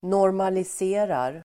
Uttal: [nårmalis'e:rar]